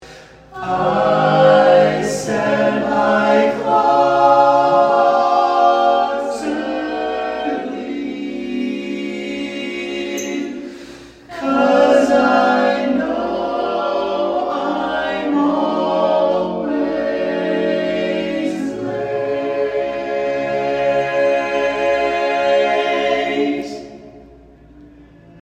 Key written in: D♭ Major
How many parts: 4
Type: Barbershop
All Parts mix: